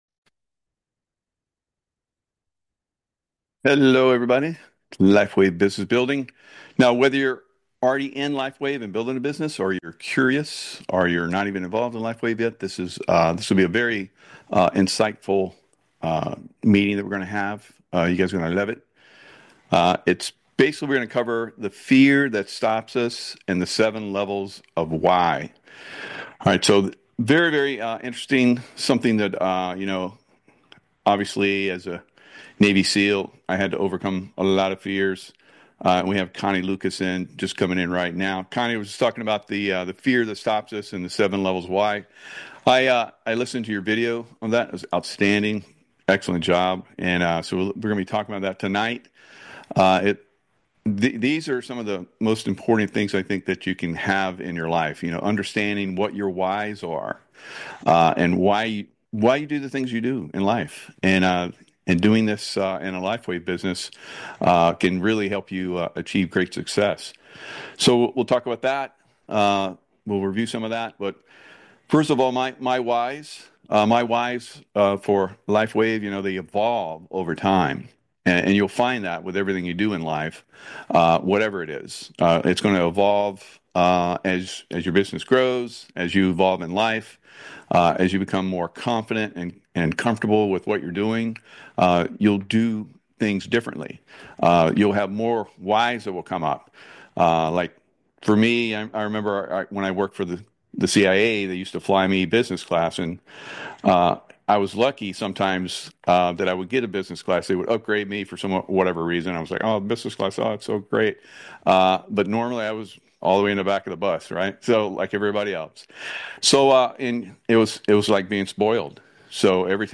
In this meeting, a former Navy Seal shares how to conquer fear and find your "why" in business. He explains how understanding your motivations can lead to success.